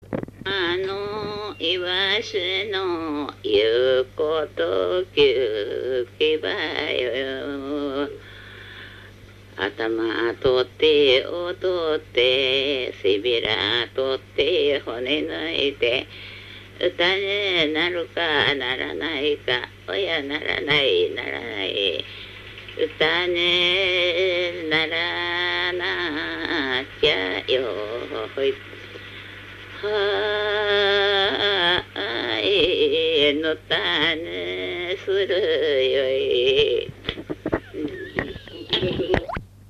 浜のいわしの言うこときけば 座興歌